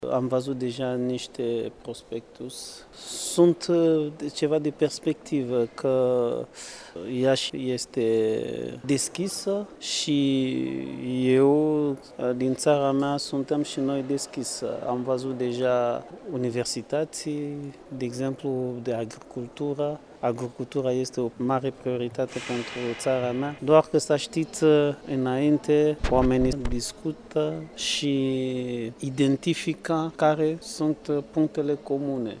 Din parte corupului diplomatic acreditat la Bucureşti, ambasadorul Republicii Democratice Congo, Nixon Bomoy a precizat că reprezentanţii ambasadelor sunt încântaţi de deschiderea Iaşului pentru mediul investiţional de pe toate meridianele şi pe de altă parte, a declarat că Iaşul are cinci universităţi foarte bine catalogate, care pot întări relaţiile cu ţările pe care reprezentanţii mediului diplomatic le reprezintă şi sunt astăzi, în vizită la Iaşi: